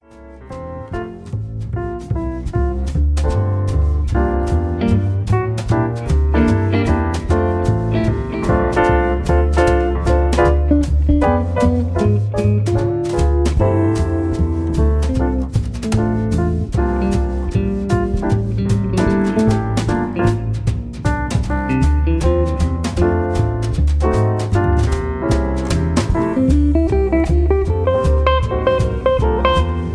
Karaoke MP3 Backing Tracks
Just Plain & Simply "GREAT MUSIC" (No Lyrics).
karaoke